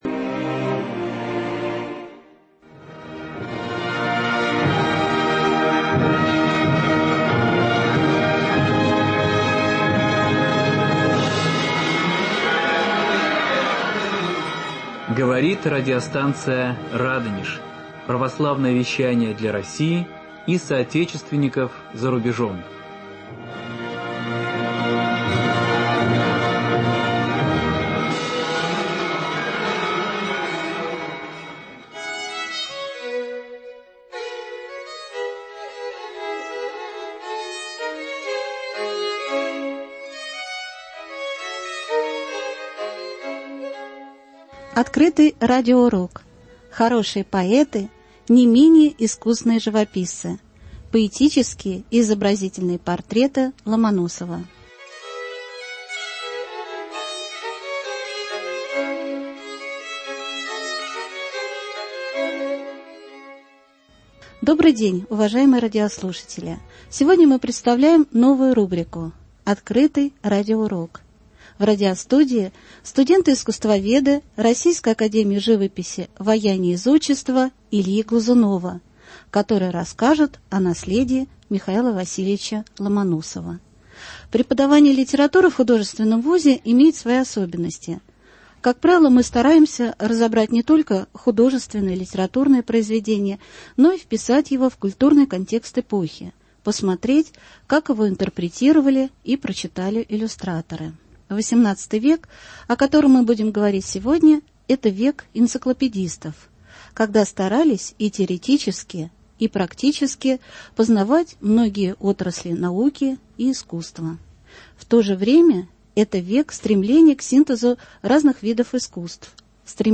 Слушать Скачать MP3-архив часа В новой рубрике «Открытый радиоурок» студенты 2-го курса факультета искусствоведения РАЖАиЗ Ильи Глазунова расскажут о поэтических и мозаичных портретах М.В. Ломоносова в контексте культуры XVIII века – эпохи энциклопедистов, когда и теоретически, и практически осваивали многие отрасли науки и искусства.